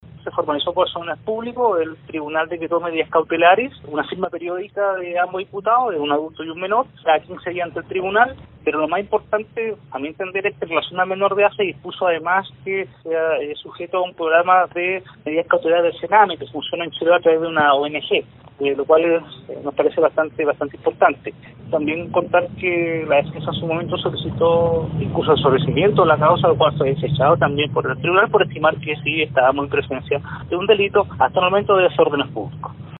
El fiscal de Ancud, Javier Calisto explicó que se formalizó a las dos personas por desórdenes públicos y sujetos a la medida cautelar de firma quincenal.